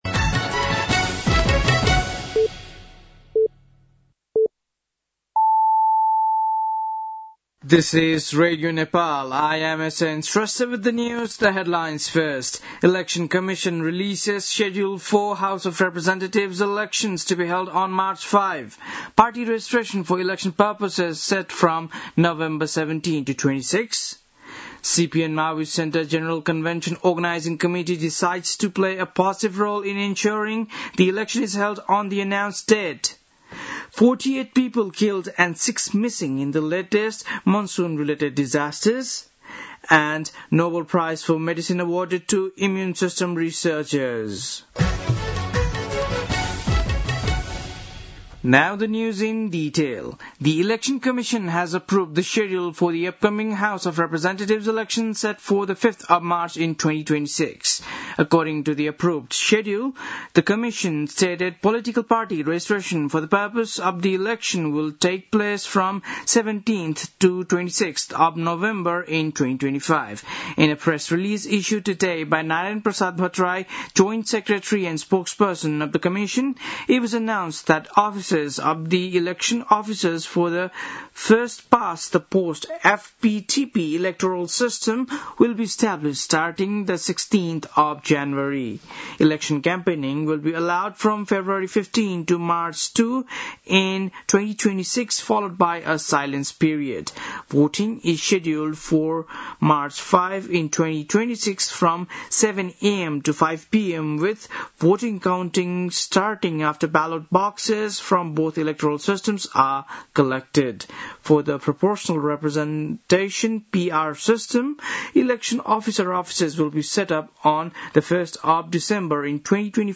बेलुकी ८ बजेको अङ्ग्रेजी समाचार : २० असोज , २०८२
8-pm-english-news-6-20.mp3